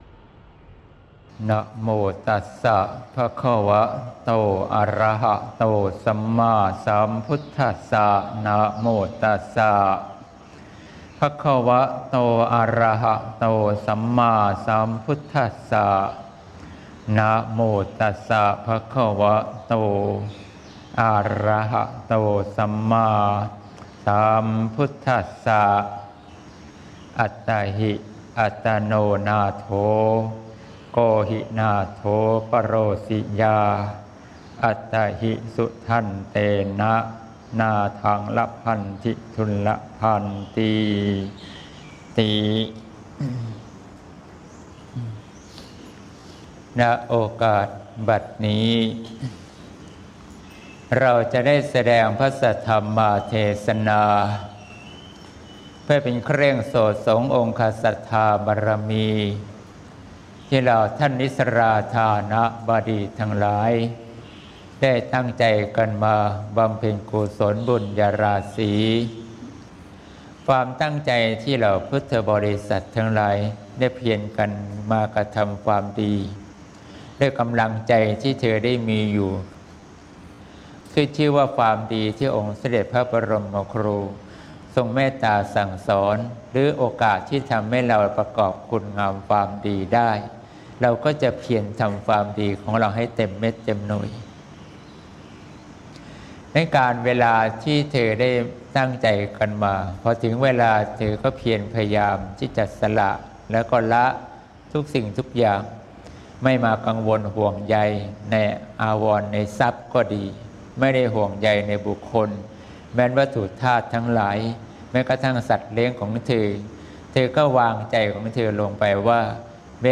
เทศน์ ตนเป็นที่พึ่งของตน (เสียงธรรม ๒๔ พ.ค. ๖๘)